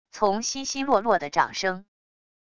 从稀稀落落的掌声wav下载